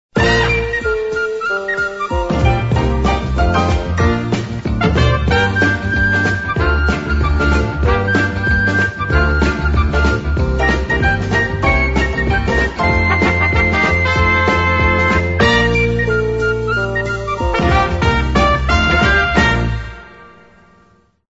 begin tune en een site met Maus filmpjes.